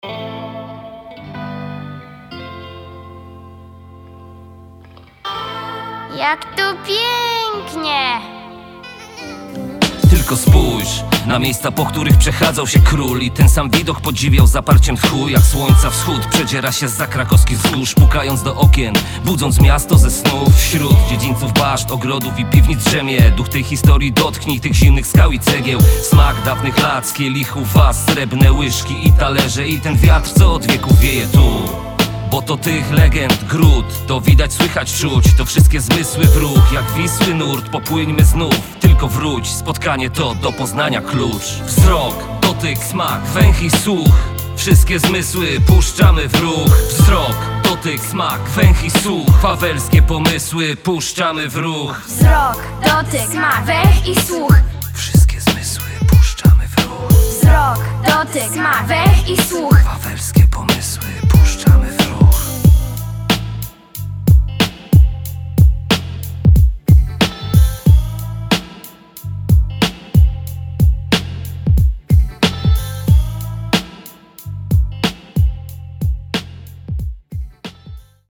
Piosenka towarzysząca cyklowi Wszystkie zmysły.